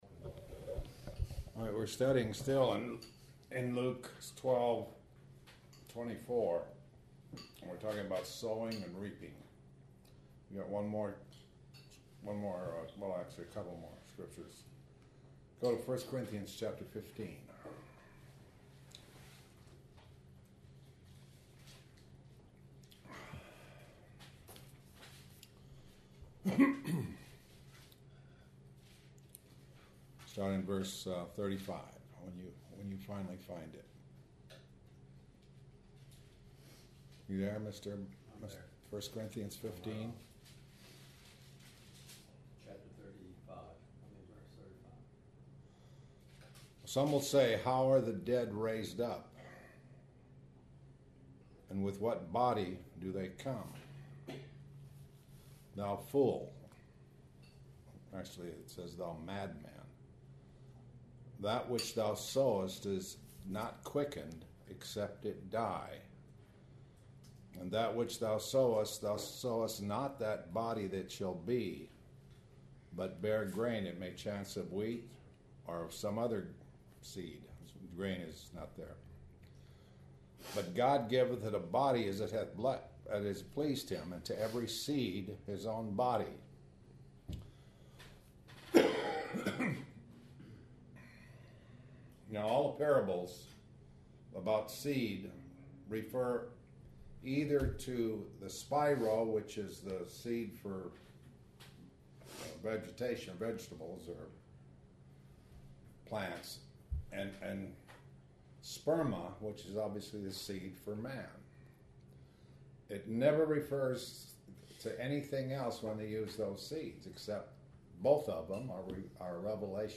This entry was posted in Morning Bible Studies .